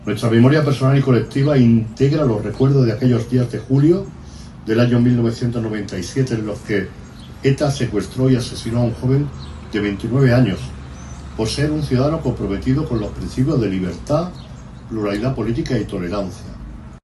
El alcalde de Antequera, Manolo Barón, ha presidido en la tarde de este jueves 10 de julio el acto de homenaje a las víctimas del terrorismo con motivo del 28 aniversario del asesinato de Miguel Ángel Blanco.
Cortes de voz